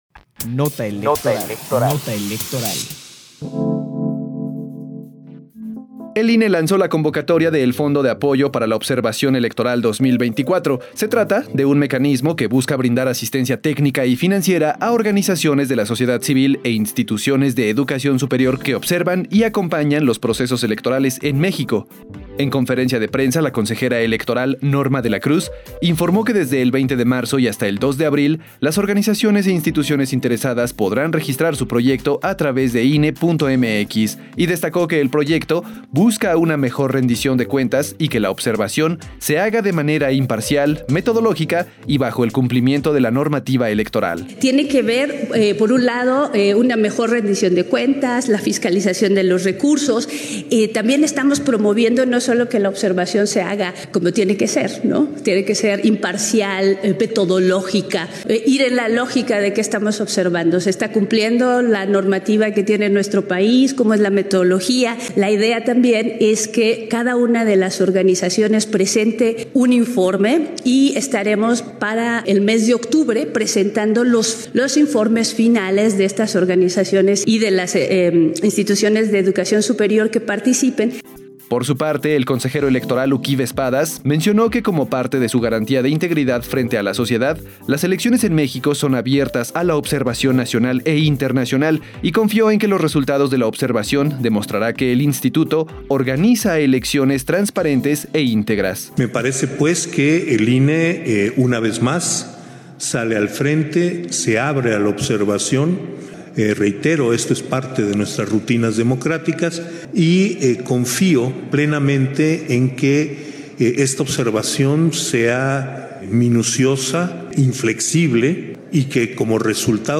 PD_1585_-CONFERENCIA-DE-PRENSA-OBSERVACION-ELECTORAL-25-marzo-2024